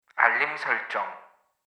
알림음 8_Radio_알림설정